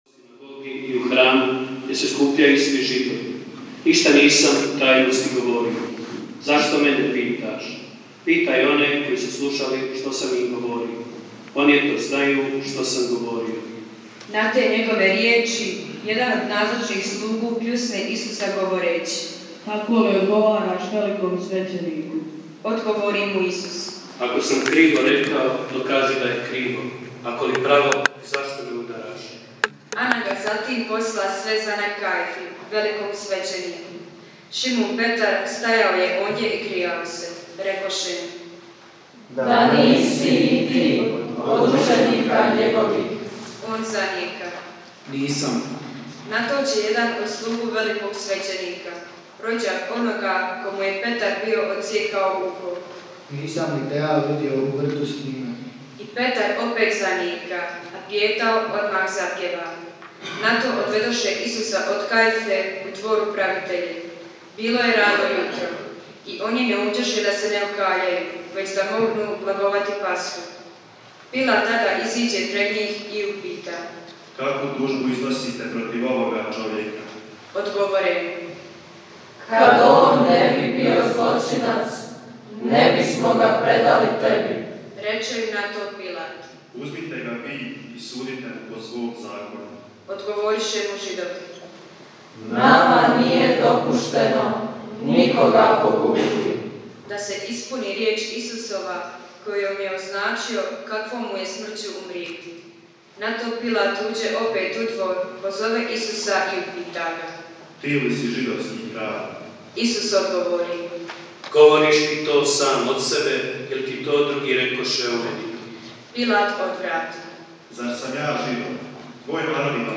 Crkva Presvetoga Srca Isusova u Kongori i danas je bila ispunjena do posljednjega mjesta, baš kao i parking za vozila, ali i čitav prostor ispred osnovne škole i sve uz samu cestu.